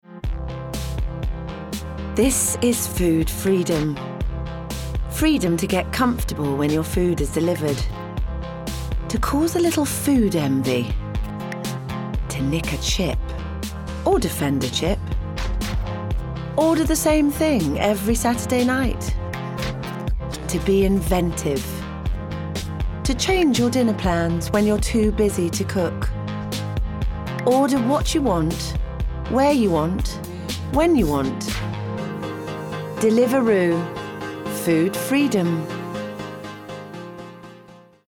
30/40's RP/Neutral, Warm/Reassuring/Engaging
Commercial Showreel